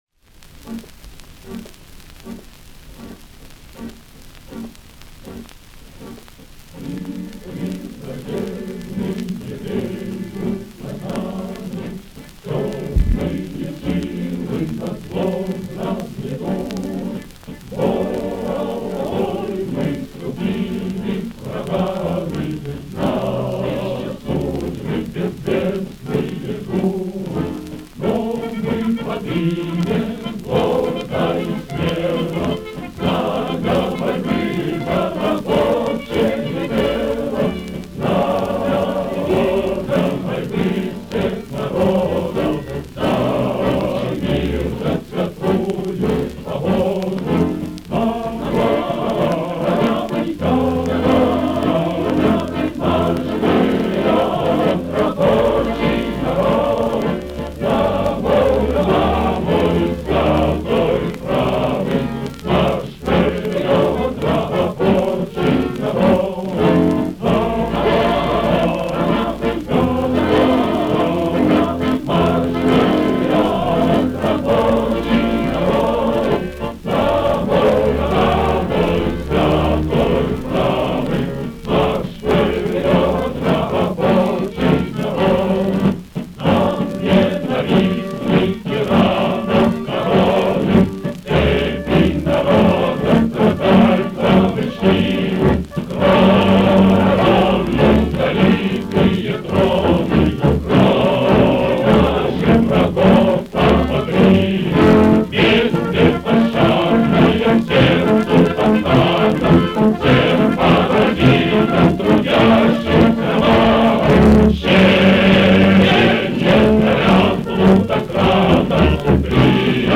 Редкая запись с блокадной пластинки